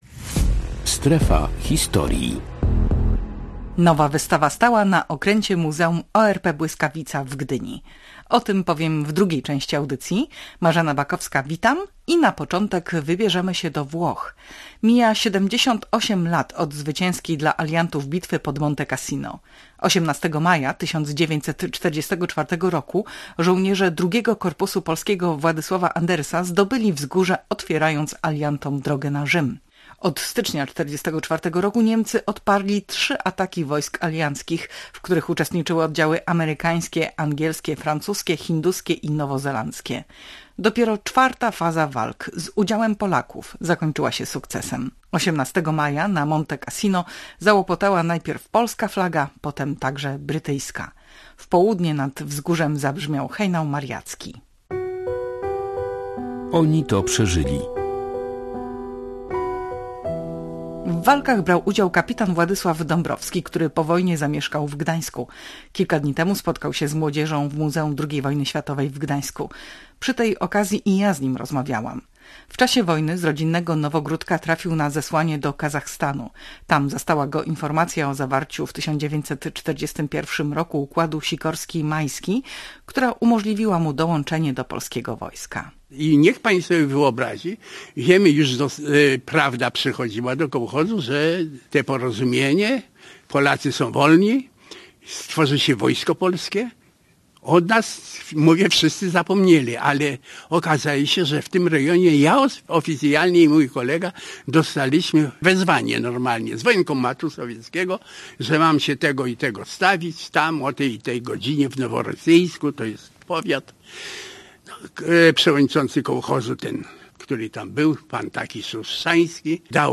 Nasza dziennikarka nagrała wspomnienia
W audycji oprowadzają po wystawie jej twórcy